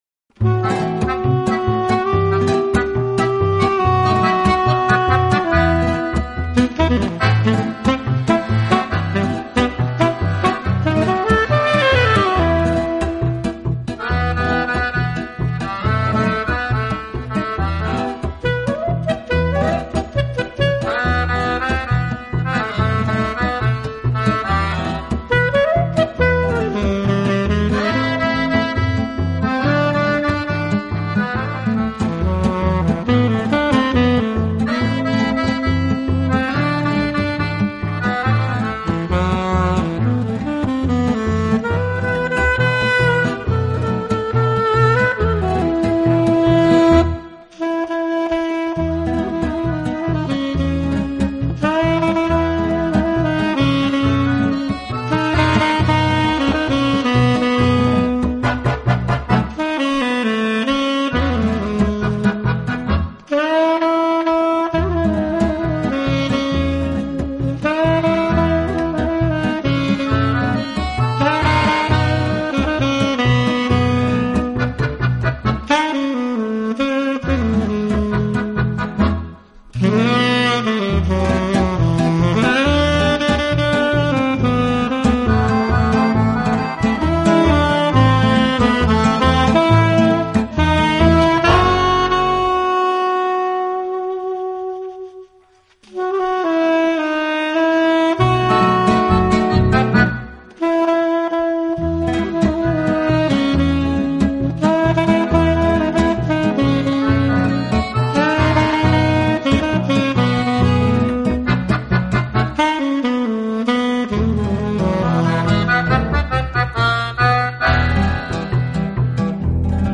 【爵士专辑】
它，配上超重低音的牛筋、浪漫的手风琴和感性的萨斯风，每张专辑均德国顶级录音室录音。
Tangos and waltzes,
Tenor Sax, Soprano Sax, C-Melody Sax, Bass Clarinet
Guitar
Accordion
Acoustic Bass, Fretless Bass, Udu Drum